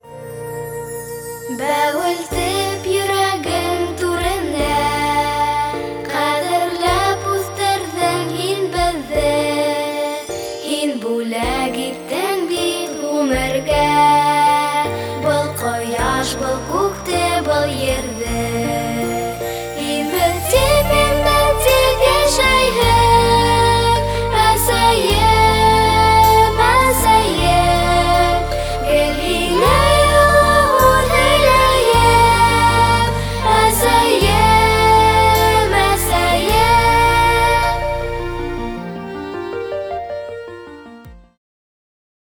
Вокал записан в небольшой звукоизолированной вокальной кабине,в которой есть неприятные резонансы. Вокал записан в Neumann 147m tube. При эквализации вокала вырезал резонансы в районе 200-350 кгц.
Пресно зучит.